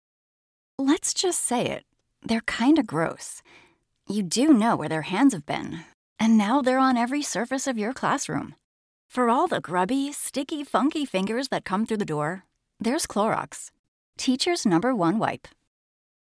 Female
Bright, Bubbly, Friendly, Warm, Confident, Natural, Young, Approachable, Conversational, Energetic, Soft, Upbeat
Microphone: TLM 102, RE20
Audio equipment: Whisper Room Booth, Apollo Twin Interface, DBX 286s preamp/processor, Aventone speakers